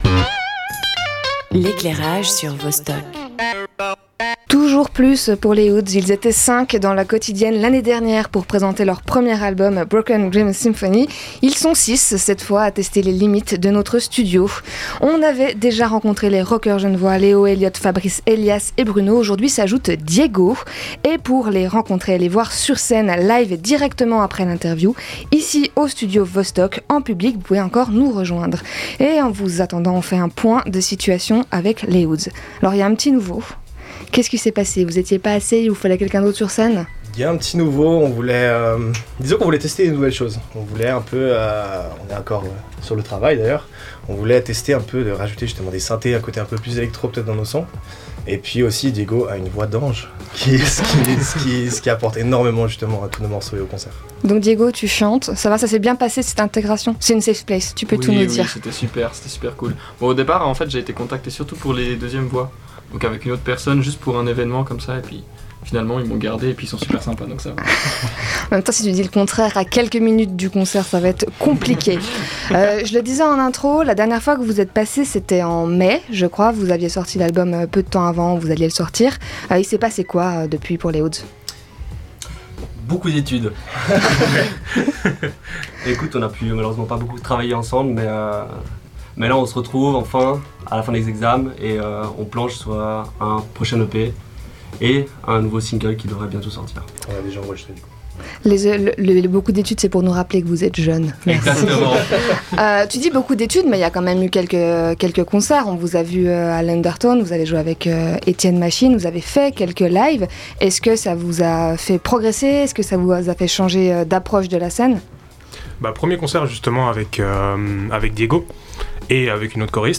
Les rockers The Hoods en interview
The Hoods étaient en interview avant leur concert du 13 février dans nos studios.